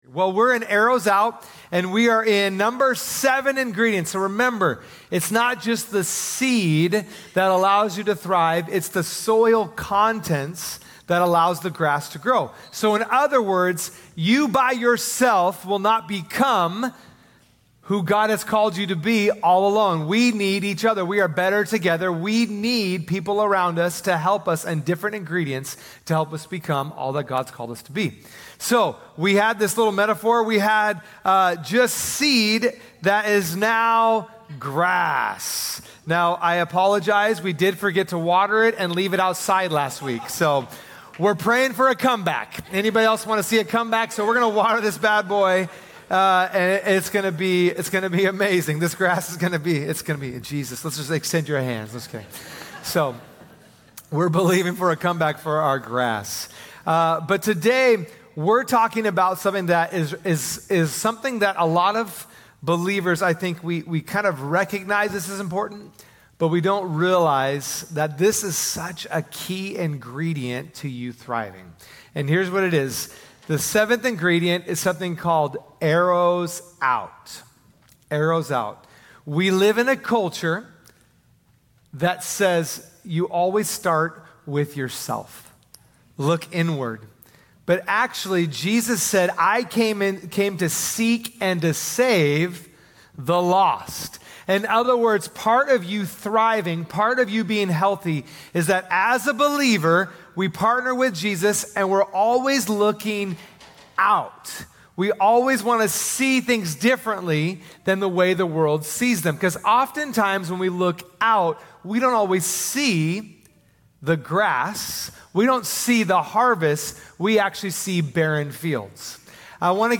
Sunday Messages from Portland Christian Center "Arrows Out" | THRIVE | Part 7 Jun 02 2024 | 00:36:54 1x 00:00 / 00:36:54 Subscribe Share Spotify RSS Feed Share Link Embed